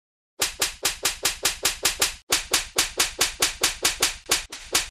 slipper.mp3